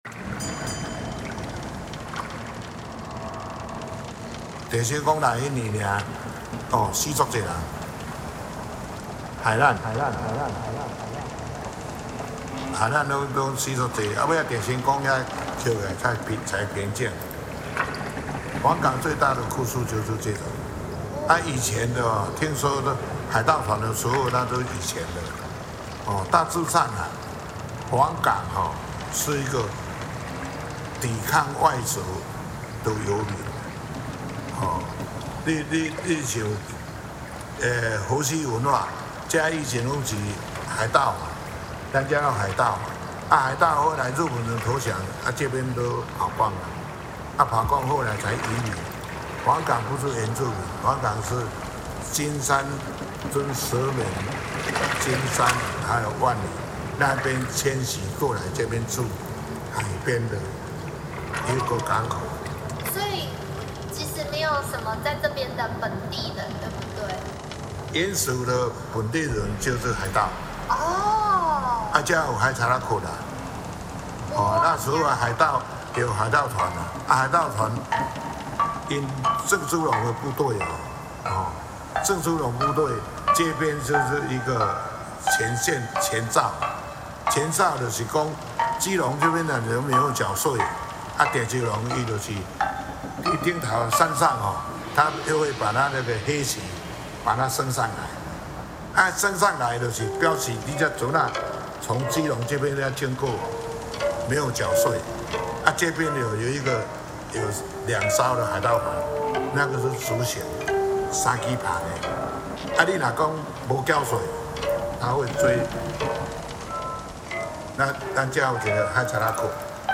These tracks feature environmental recordings, oral histories, and storytelling, echoing the voices, rhythms, and transformations of coastal life in Jinshan.